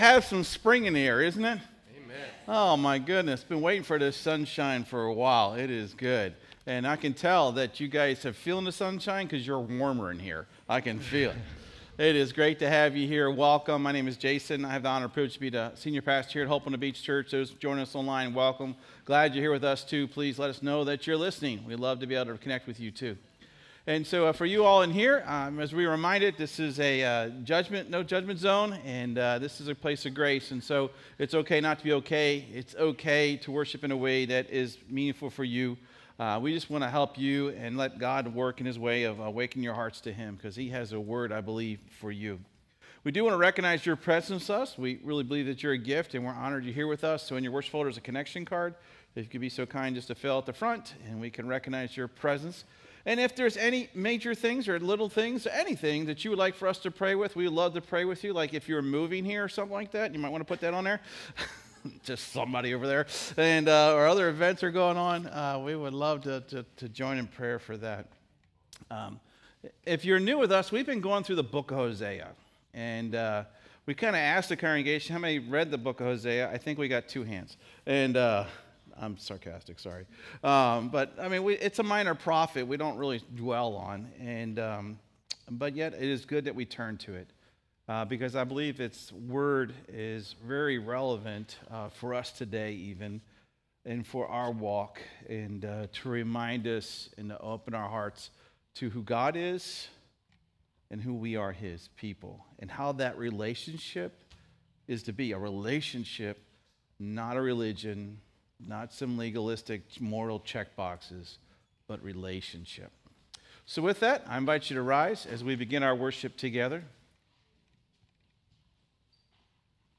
This sermon is a tender call to return to the Lord, who wounds to heal and disciplines in love. Though we have stumbled in sin, God invites us to come with honest confession and promises restoration and new life—fulfilled in Christ’s finished work on the cross.